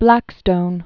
(blăkstōn, -stən), Sir William 1723-1780.